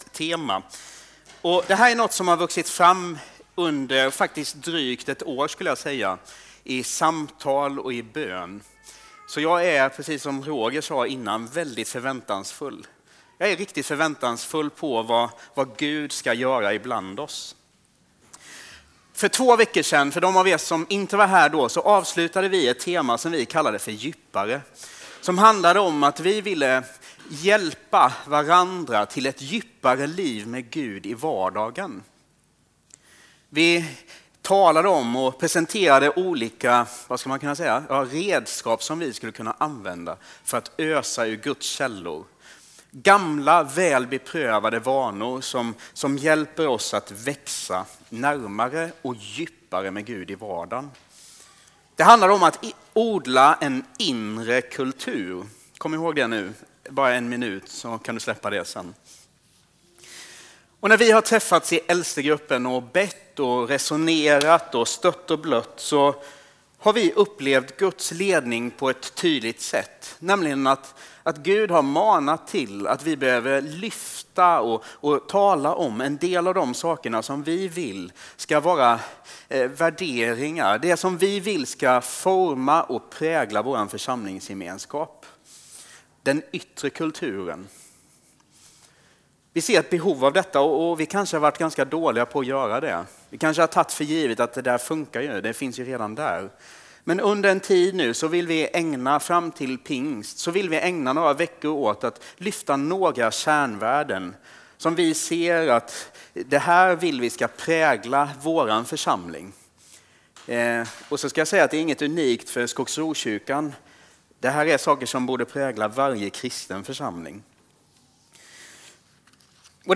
A predikan from the tema "Vi."